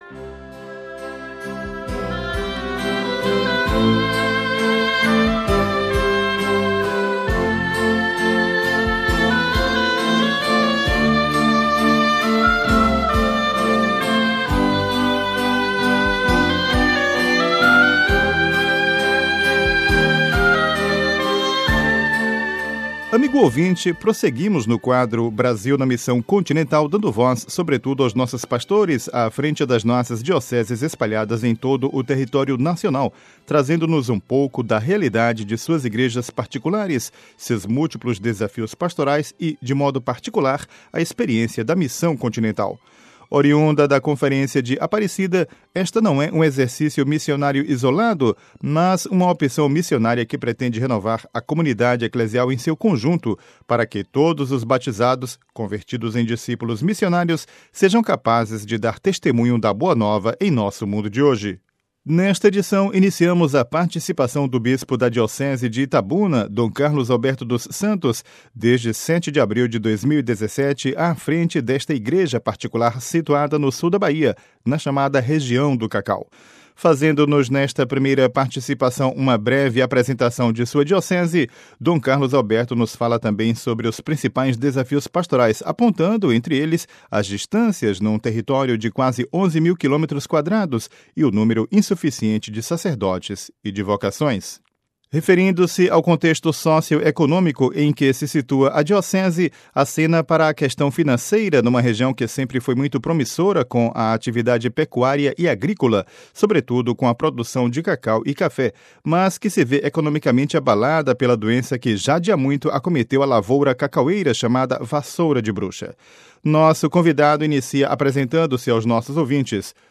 Nesta edição iniciamos a participação do bispo da Diocese de Itabuna, Dom Carlos Alberto dos Santos, desde 7 de abril de 2017 à frente desta Igreja particular situada no sul da Bahia, na chamada região do cacau.